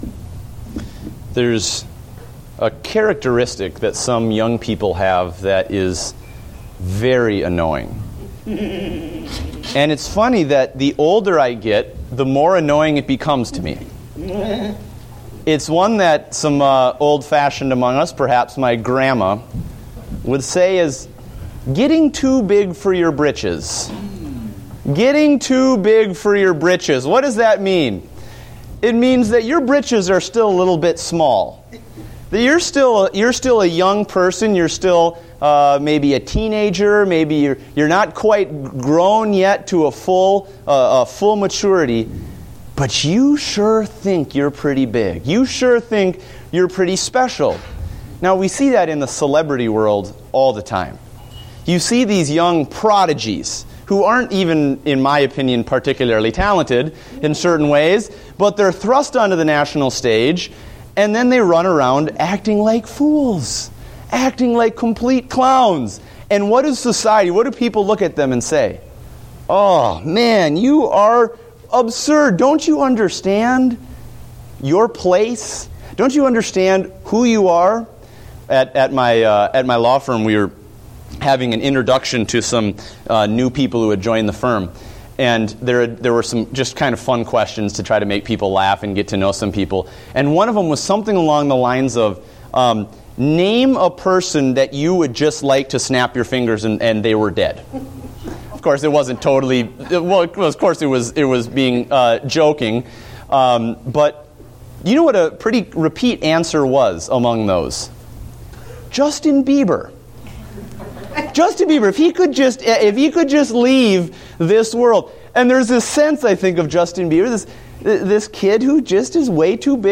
Date: January 11, 2015 (Adult Sunday School)